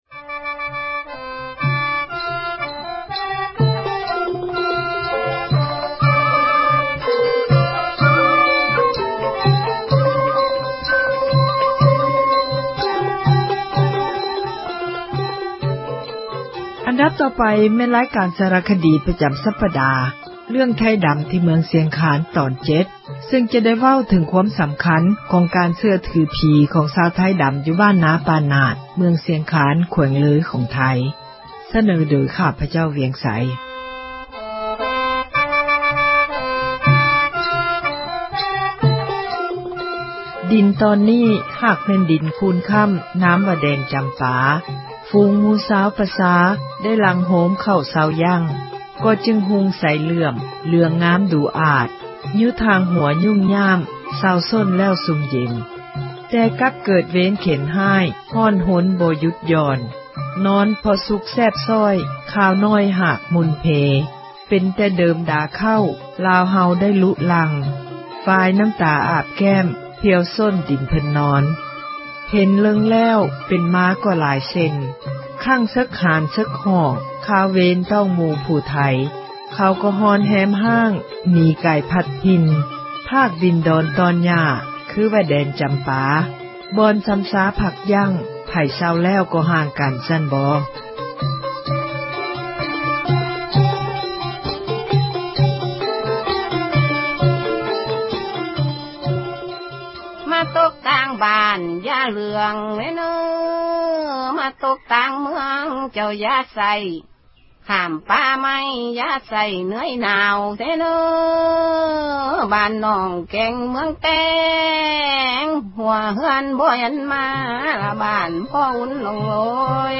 ສາຣະຄະດີ ເຣື້ອງ ”ໄທດຳ ທີ່ເມືອງ ຊຽງຄານ”ຕອນທີ 7 ໃນມື້ນີ້ ຈະໄດ້ເລົ່າເຖິງ ຄວາມສຳຄັນ ຂອງການ ເຊື່ອຖືຜີ ຂອງຊາວ ໄທດຳ ບ້ານນາ ປ່ານາດ ເມືອງ ຊຽງຄານ ແຂວງ ເລີຍ ຂອງໄທ.